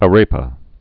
(ə-rāpə)